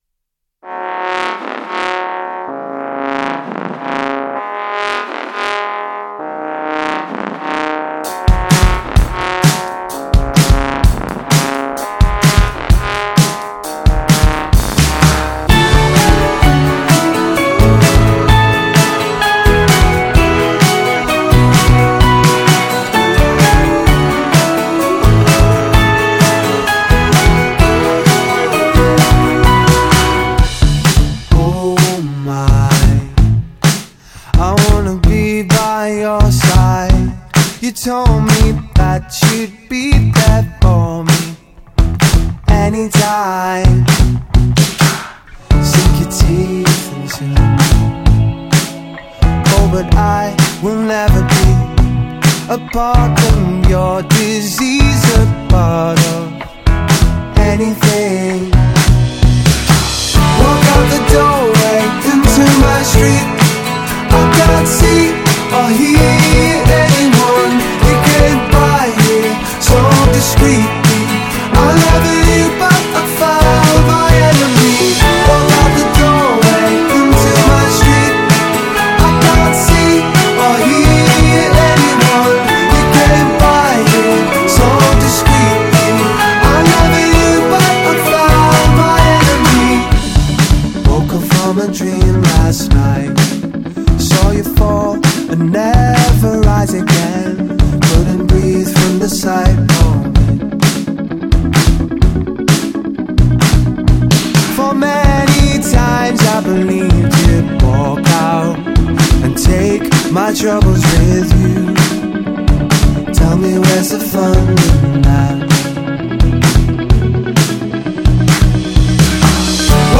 alternative-pop quintet